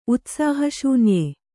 ♪ utsāha śunye